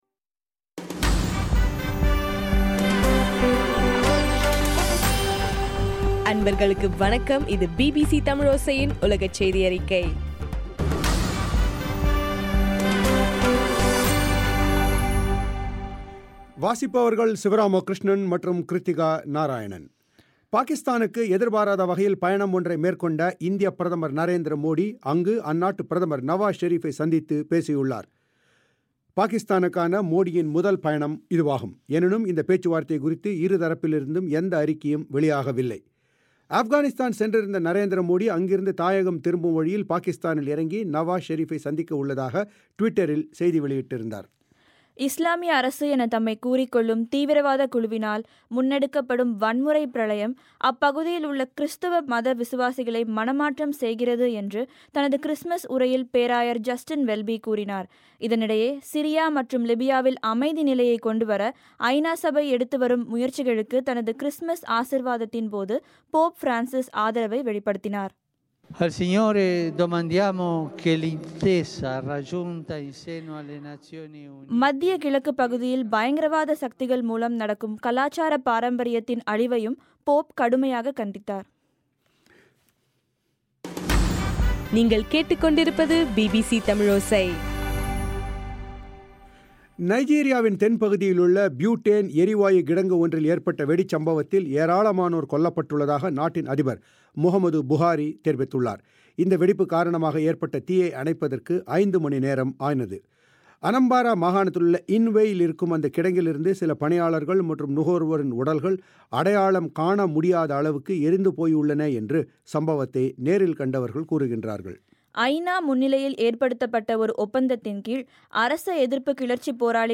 டிசம்பர் 25, 2015 பிபிசி தமிழோசையின் உலகச் செய்திகள்